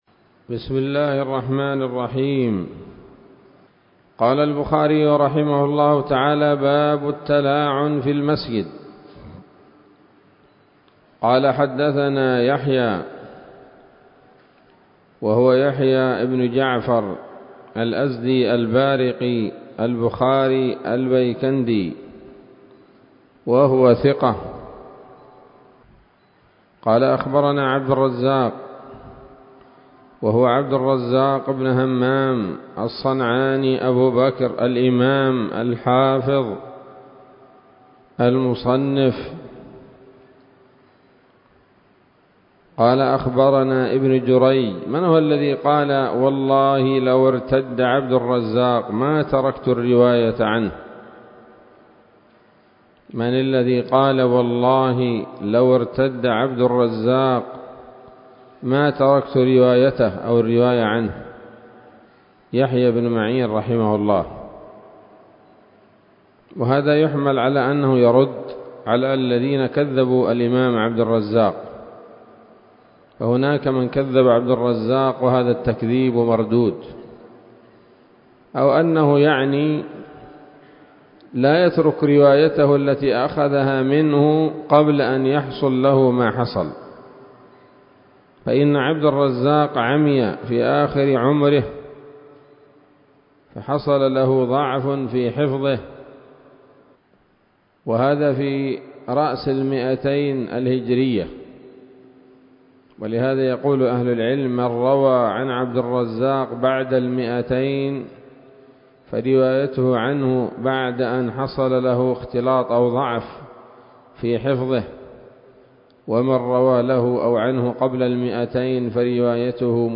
الدرس الرابع والعشرون من كتاب الطلاق من صحيح الإمام البخاري